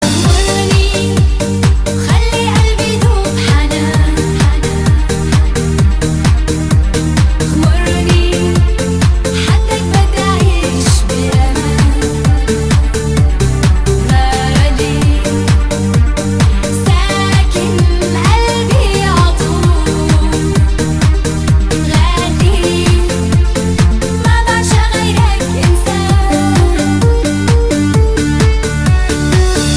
• Качество: 320, Stereo
поп
женский вокал
мелодичные
dance
club
восточные
красивый женский голос
звонкие